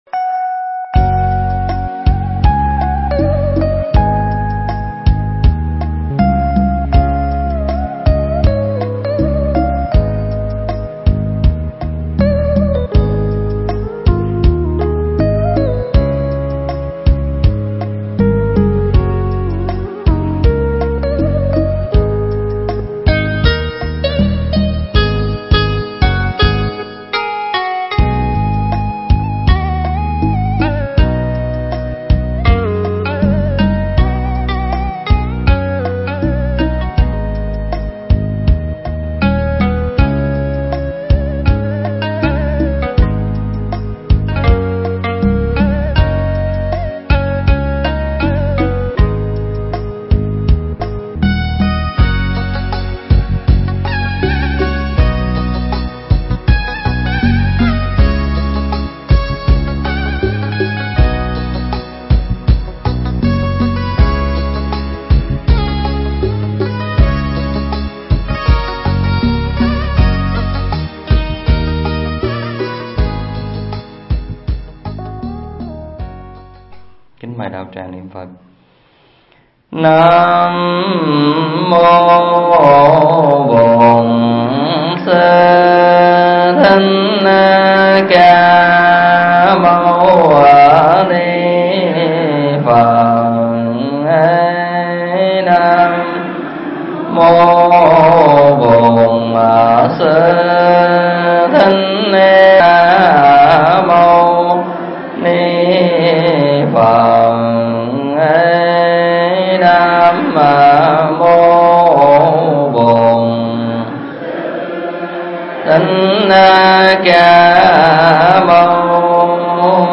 Mp3 Thuyết Giảng Bi Trí Dũng
giảng tại Từ Bi Đạo Tràng (Bang Texas, Hoa Kỳ)